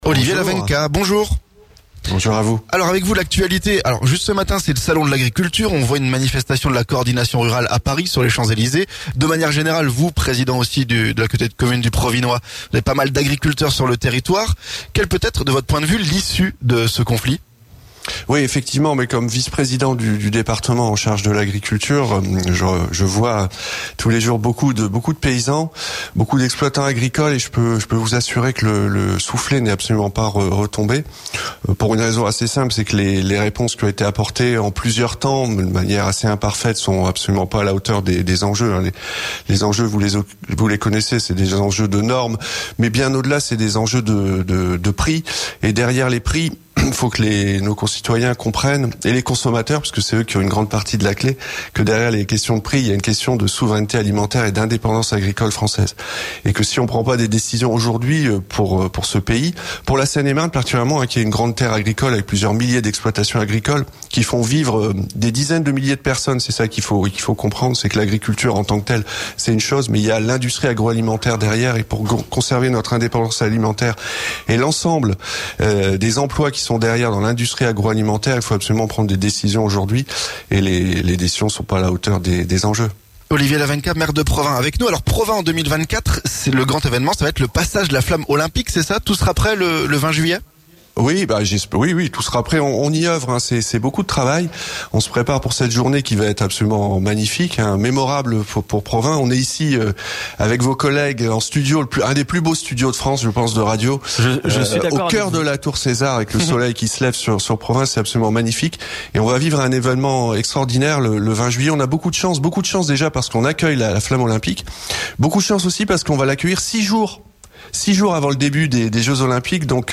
REPLAY - Olivier Lavenka, maire de Provins, invité de la rédaction
Ce matin, à l'occasion de l'émission spéciale des 2 du matin depuis Provins, le maire, Olivier Lavenka, a répondu aux questions de la rédaction. Au menu: crise agricole et flamme olympique à Provins le 20 juillet.